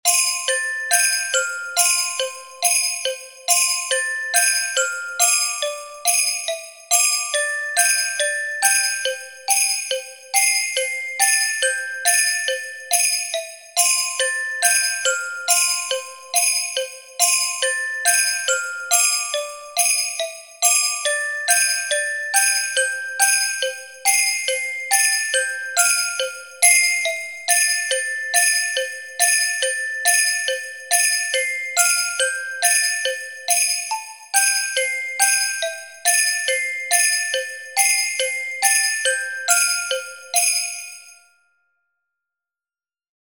Listen to the complete score using adagio tempo marking.